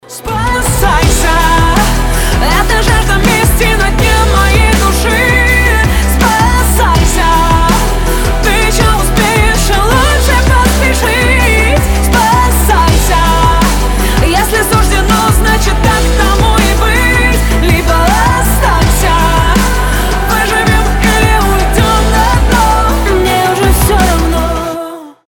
• Качество: 320, Stereo
громкие
женский голос